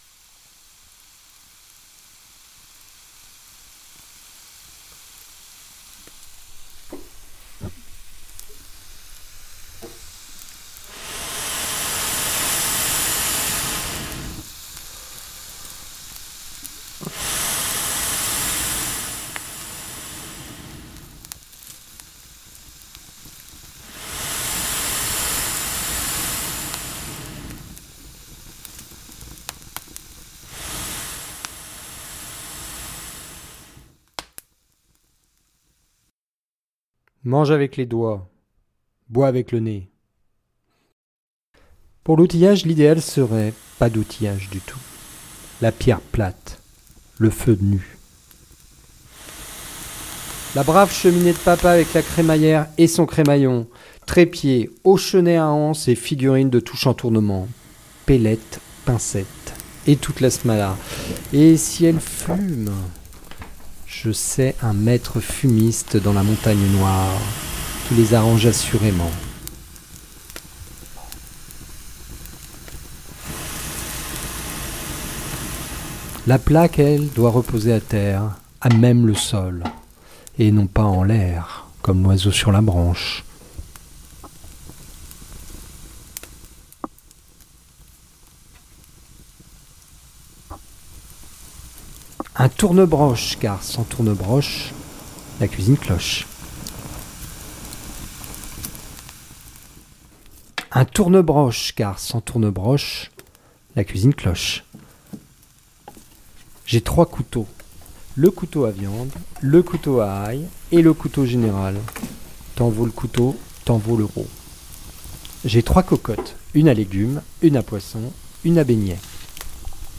Lecture au coin du feu de la cuisine paléolithique
Extraits_cuisine_paléolithique_Joseph_Delteil.mp3